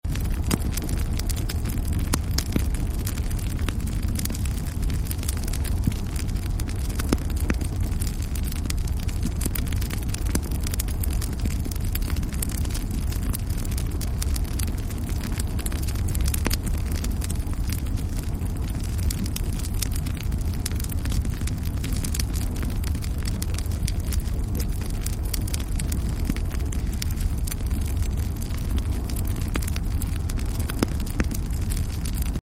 炎が燃え盛る様子を表現した効果音です。ごうごうとした音が繰り返され、ぱちぱちとした音が交互に鳴り響きます。